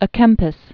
(ə kĕmpĭs, ä), Thomas